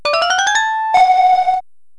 Retrouver, ci-dessous, le fichier sonore de début de vert R12.